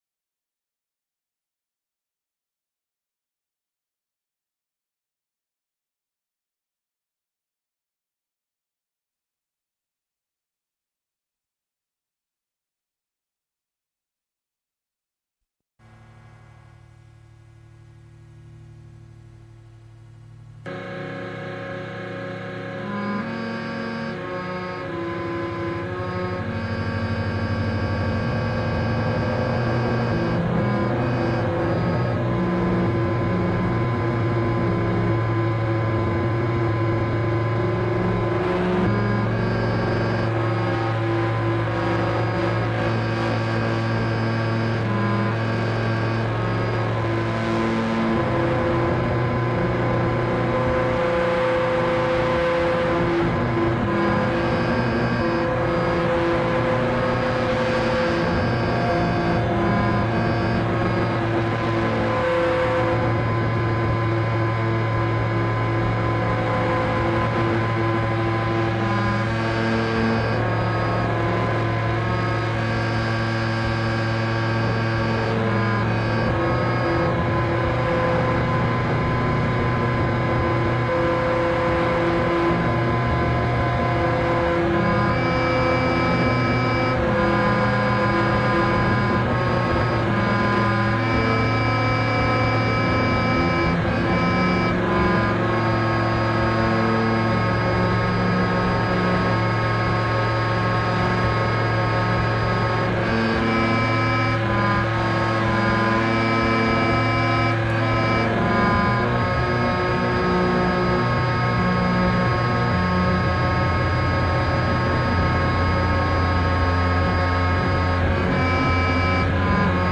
pop a pleurer expé lo-fi